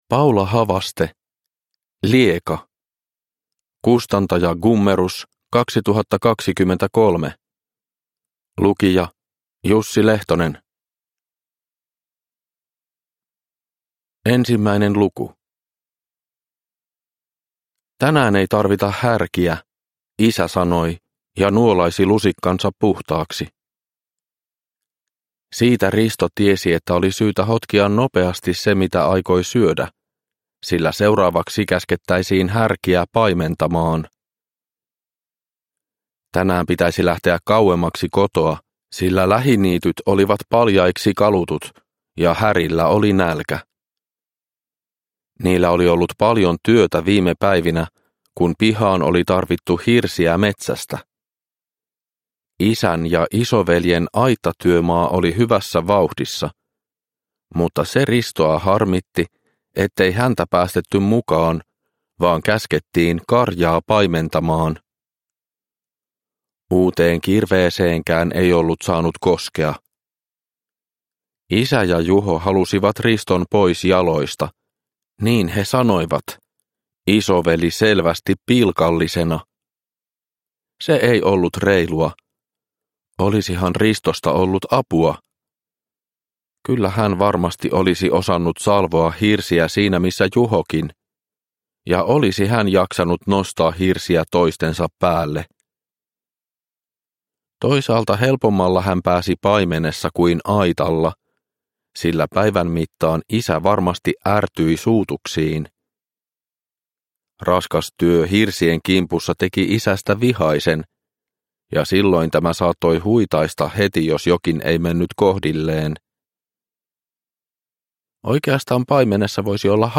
Lieka – Ljudbok – Laddas ner